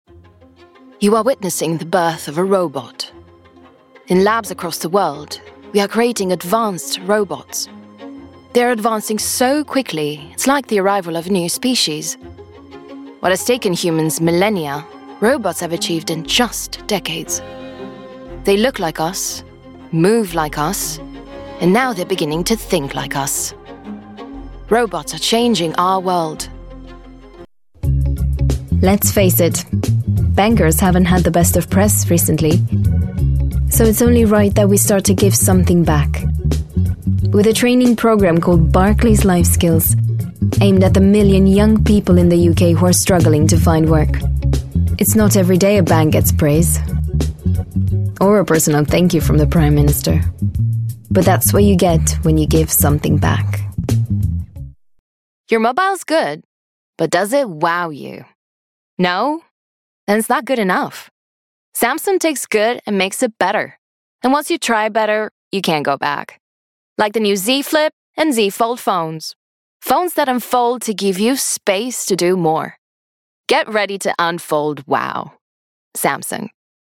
Eastern European, Romanian, Female, 20s-30s
English (Romanian Accent)